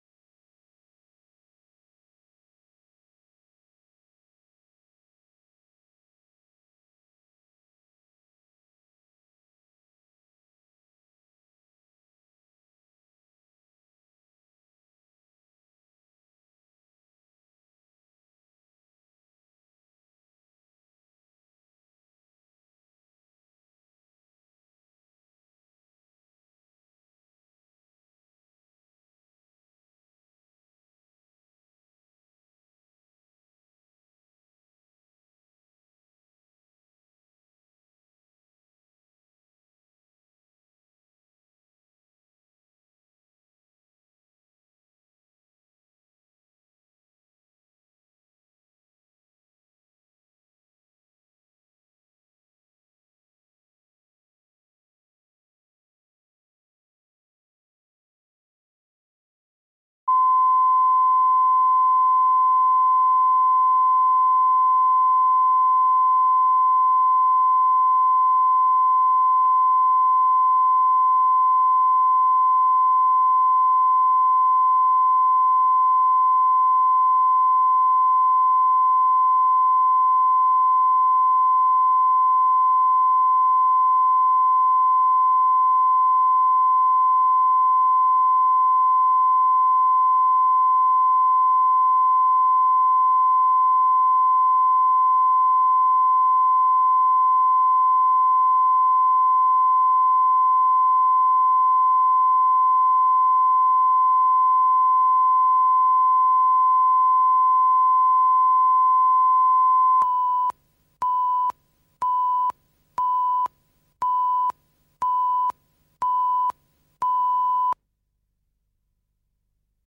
Аудиокнига Шарики цвета моря | Библиотека аудиокниг
Прослушать и бесплатно скачать фрагмент аудиокниги